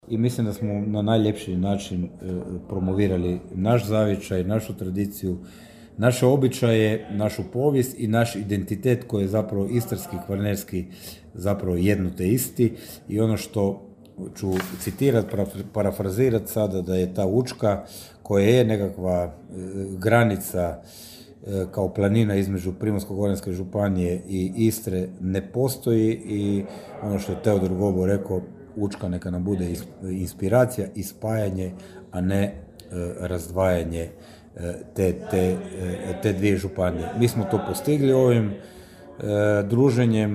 Zadovoljan događajem bio je načelnik Mošćeničke Drage Riccardo Staraj: (